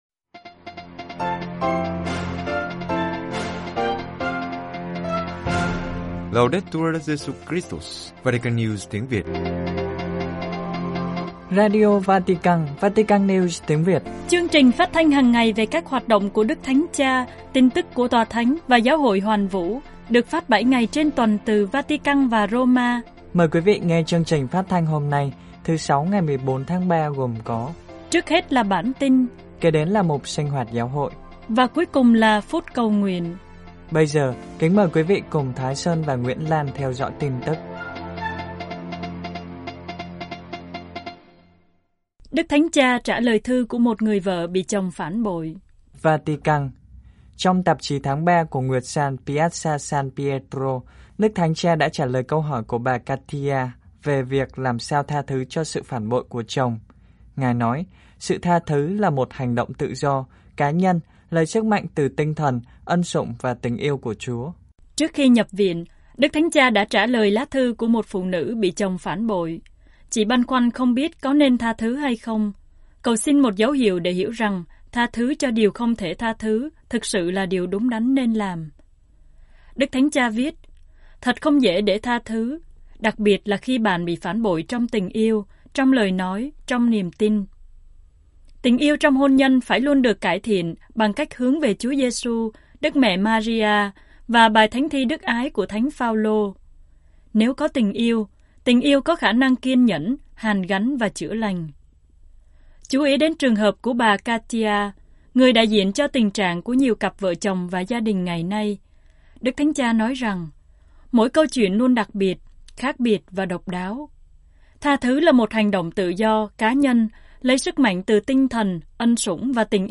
Chương trình phát thanh tiếng Việt của Vatican News phát mỗi ngày 25 phút, gồm các mục chính như: Tin tức, Sinh hoạt Giáo Hội, Gặp Đức Giáo Hoàng, Gương chứng nhân, Suy niệm Lời Chúa, Bình luận, Giáo hoàng và người trẻ, Phút cầu nguyện...